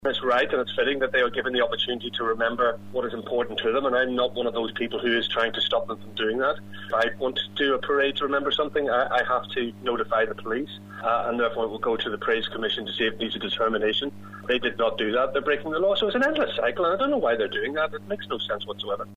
Speaking to Q Radio News, UUP MLA Doug Beattie says there's a process which must be adhered to.